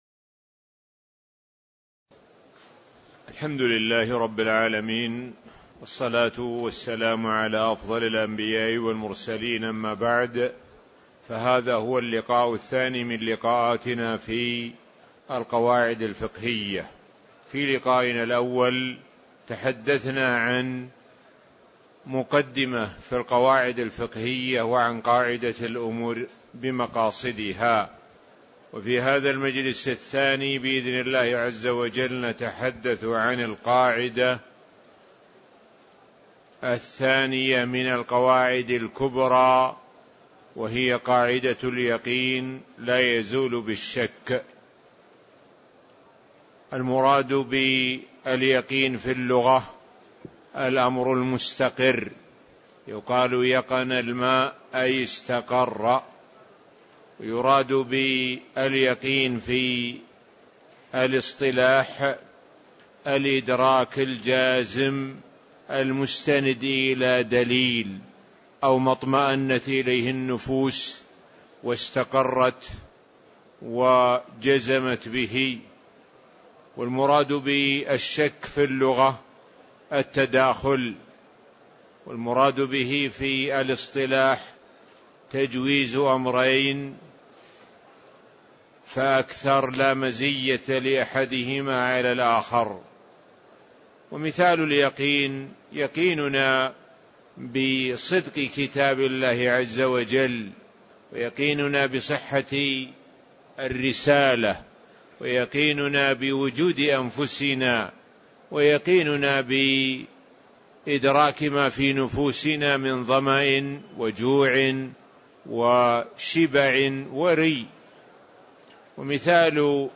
تاريخ النشر ١ جمادى الآخرة ١٤٣٧ هـ المكان: المسجد النبوي الشيخ: معالي الشيخ د. سعد بن ناصر الشثري معالي الشيخ د. سعد بن ناصر الشثري القاعدة الثانية والثالثة (02)